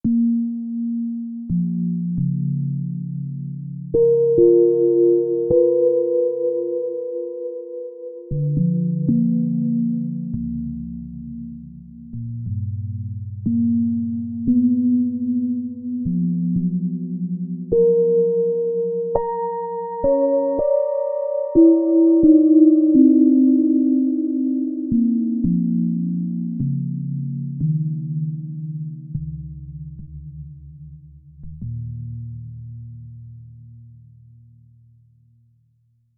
Bit more subtle. Tracking better.